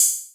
SZ HHAT 16.wav